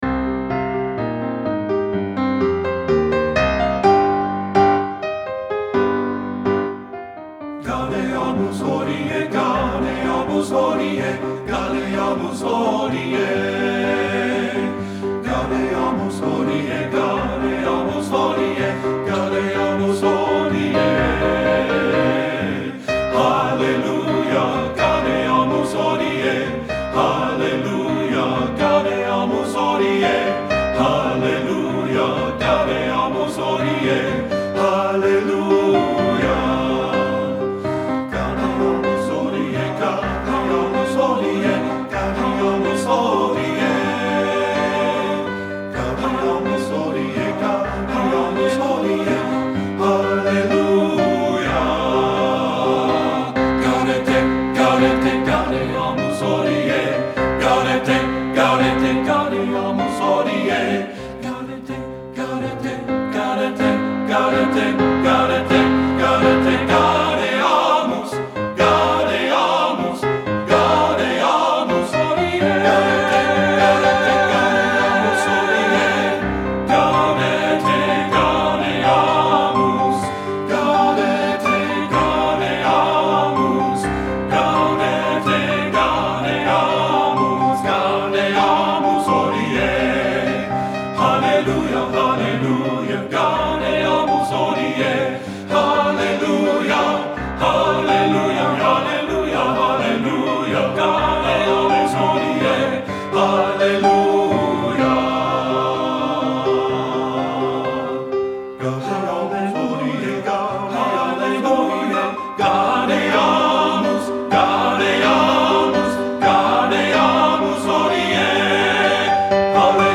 Voicing: TBB and Piano